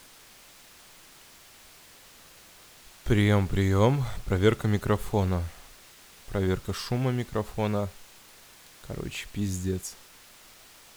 Белый шум у микрофона rode nt1-a
Всем привет, столкнулся с проблемой белого шума на микрофоне. Шум постоянный и при увеличении чувствительности микрофона и усиления сигнала в наушниках он становится громче.
Сетап: Микро: Rode nt1-a Звуковуха: Arturia minifuse.